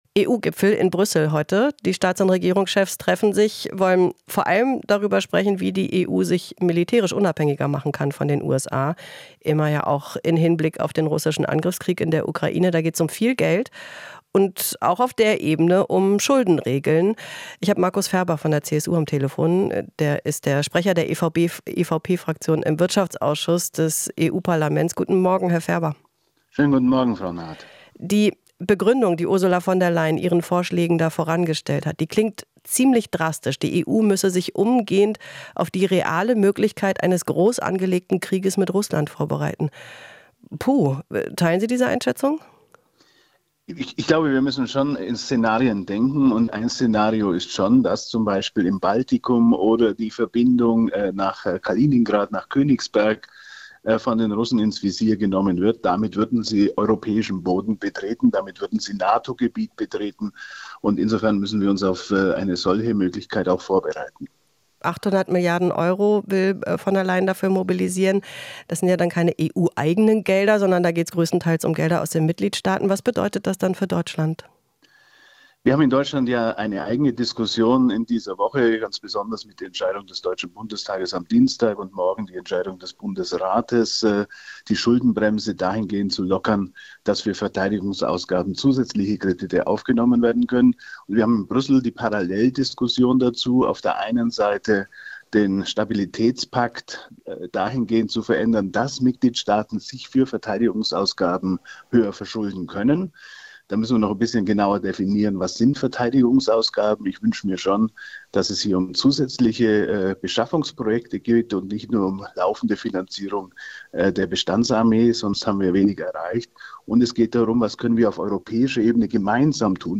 "Wir müssen schon in Szenarien denken", sagt dazu der CSU-Politiker Markus Ferber, Sprecher der EVP-Fraktion im Wirtschaftsausschuss des EU-Parlaments.